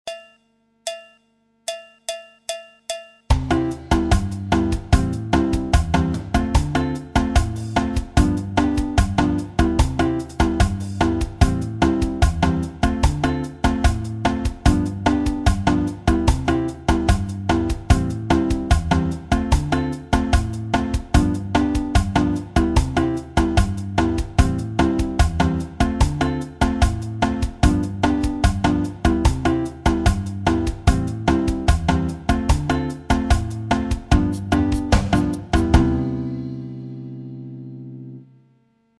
La guitare brésilienne et les rythmes brésiliens
J'ai mis un pattern de batterie avec clave samba et tambourim 1 samba.
La samba et le partido alto
Pour le mettre en valeur on utilise les cordes aigues de la guitare.